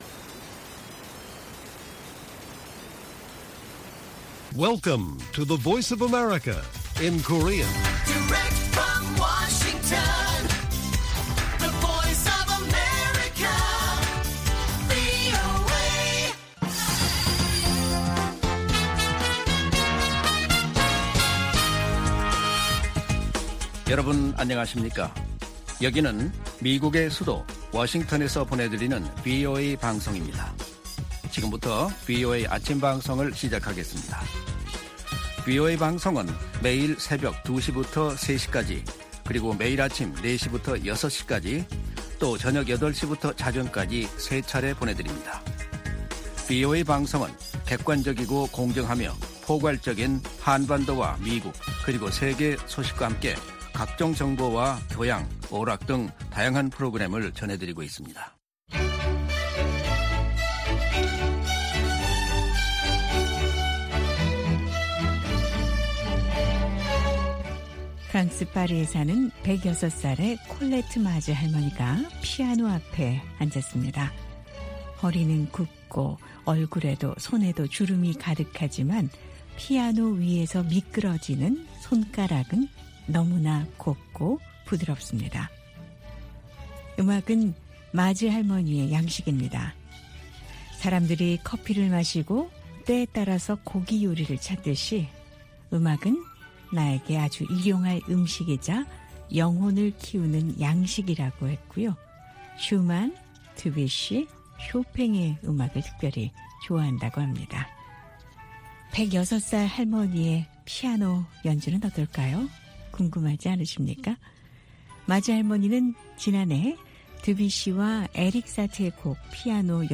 VOA 한국어 방송의 월요일 오전 프로그램 1부입니다.